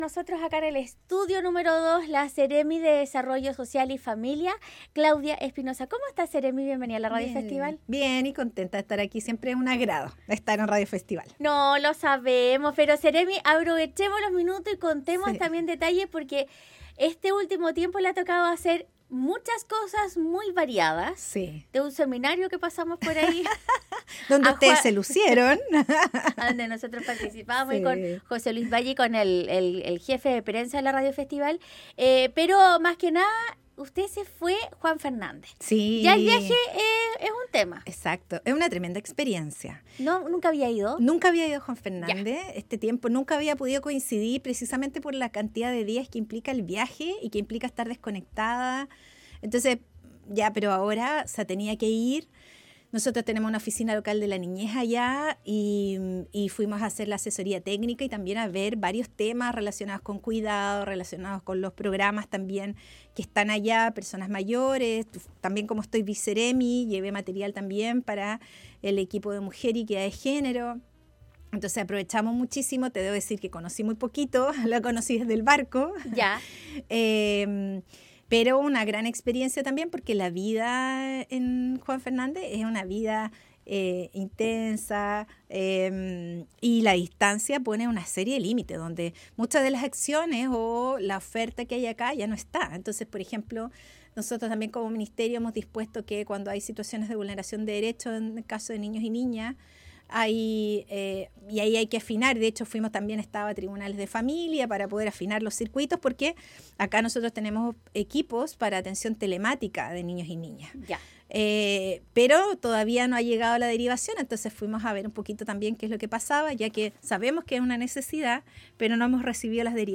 La Seremi Claudia Espinoza estuvo en los estudios de Radio Festival para contar detalles del trabajo realizado en el archipiélago de Juan Fernández, el funcionamiento del código azul y Campañas de Familias de Acogida